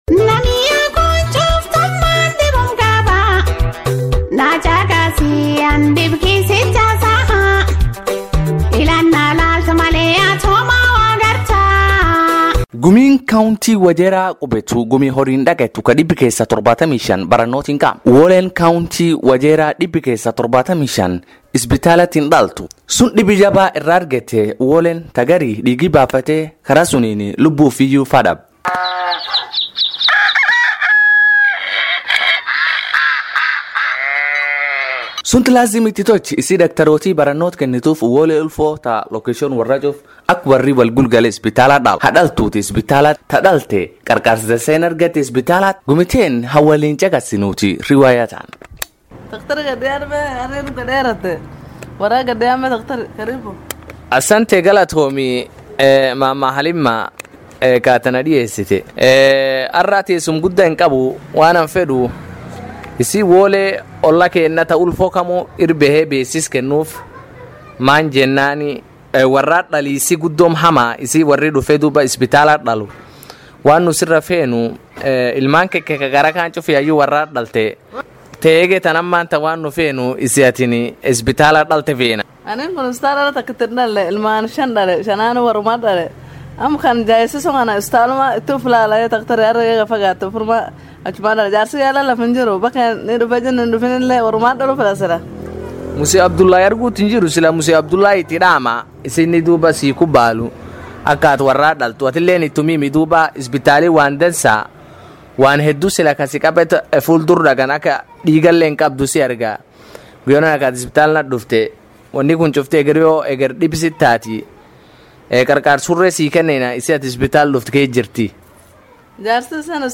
An epic Somali Radio drama scripted and characterized by Toquma Theatre group in Wajir county. The drama seeks to address state of accountability and transparency on service delivery in the health sector.